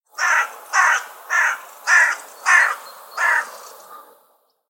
دانلود صدای قار قار کلاغ از ساعد نیوز با لینک مستقیم و کیفیت بالا
جلوه های صوتی